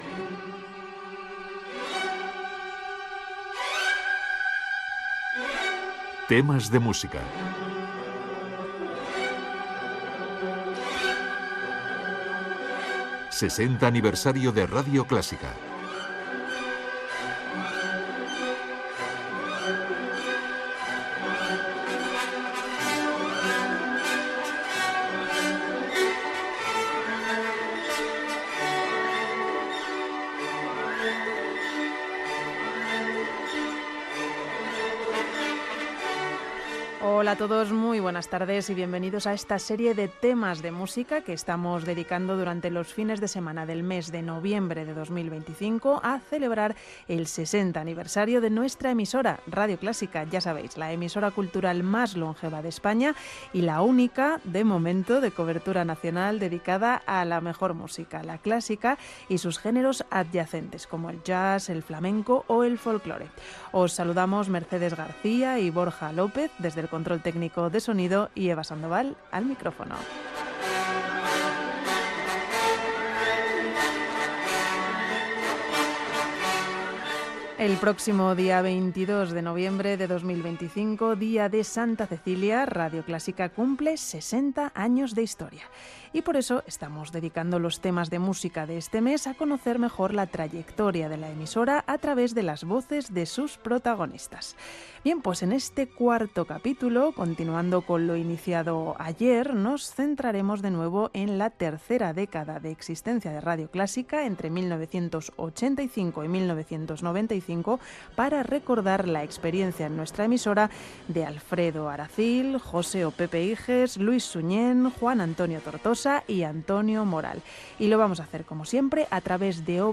Gènere radiofònic Musical